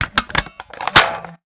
flamethrower_reload_01.wav